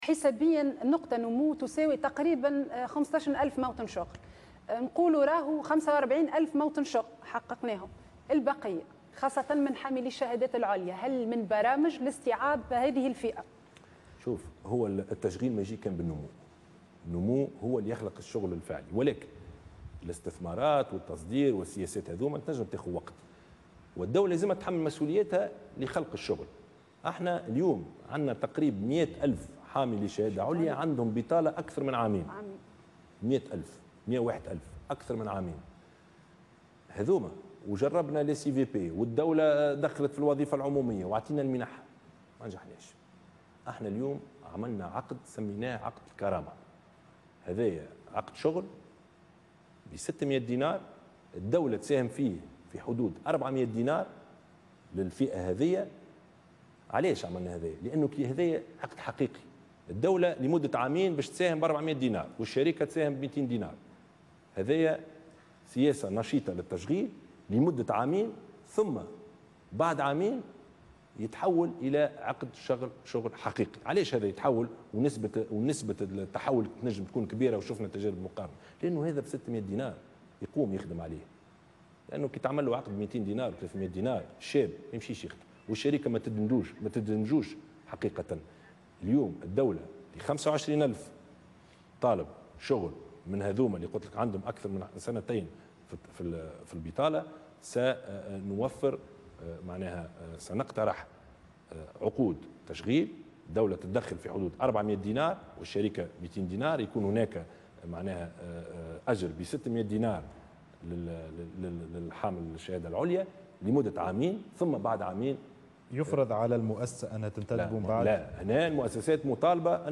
أعلن رئيس الحكومة يوسف الشاهد في حوار على القناة الوطنية الأولى مساء اليوم الأربعاء 28 سبتمبر 2016 أن الدولة أعدت برنامجا لتشغيل حاملي الشهادات العليا الذين يشكون من البطالة منذ نحو سنتين في اطار "عقد الكرامة".